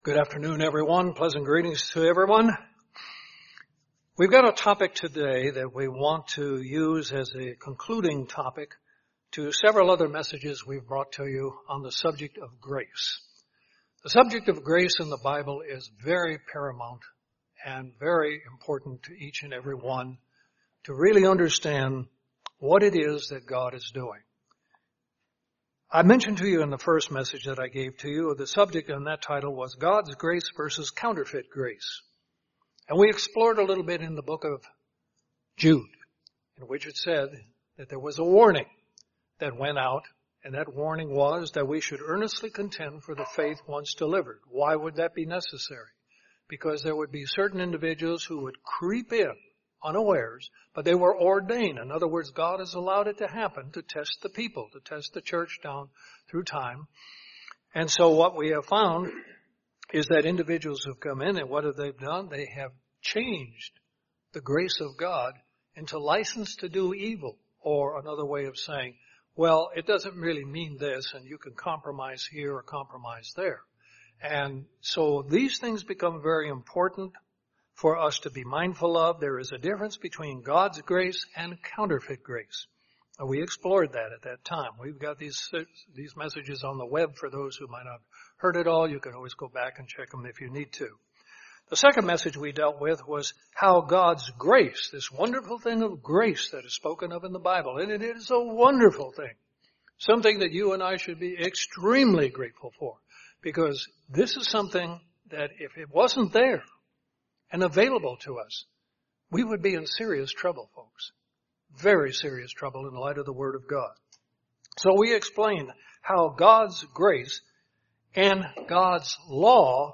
Conclusion to a riveting sermon series on grace. This sermon explains how God's grace is central to God's plan for the salvation of mankind.